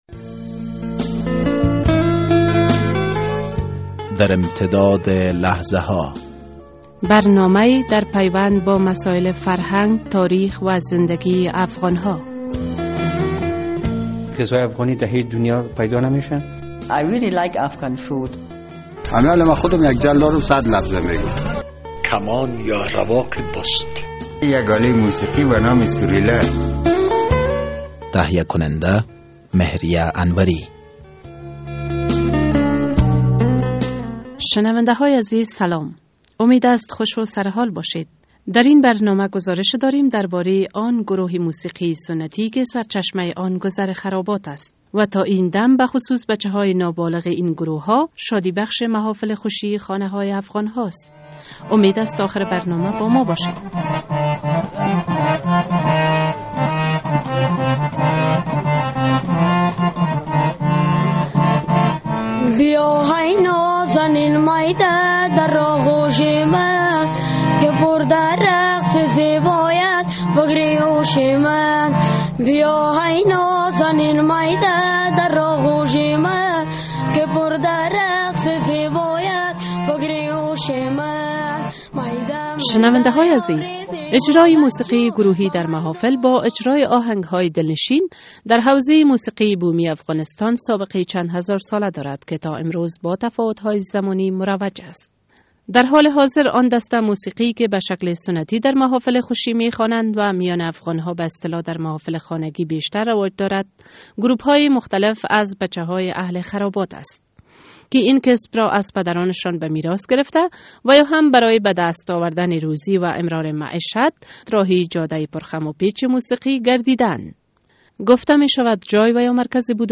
موسیقی سنتی محافل خانگی
اجرای موسیقی گروهی در محافل با اجرای آهنگ های دلنشین در حوزه موسیقی بومی افغانستان سابقه چند هزار ساله دارد که تا امروز با تفاوت های زمانی مروج است.